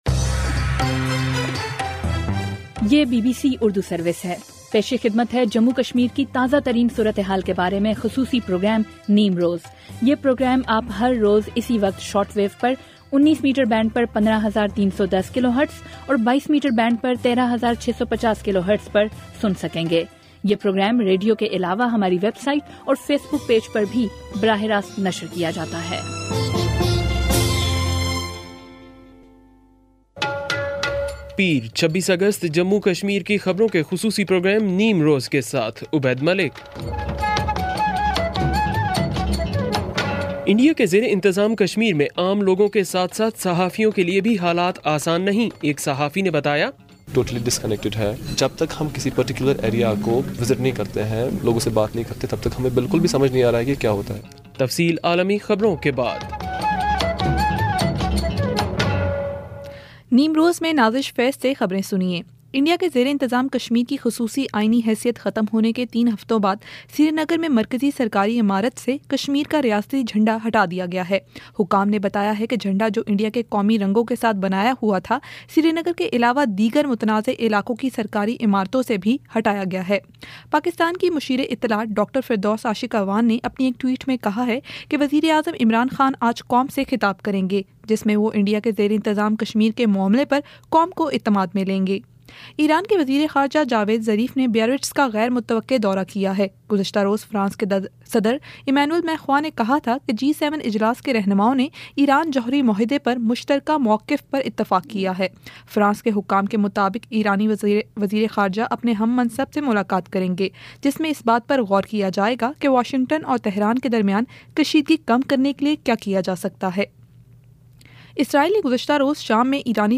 بی بی سی اردو سروس سے جموں اور کشمیر کی خبروں کا خصوصی پروگرام نیم روز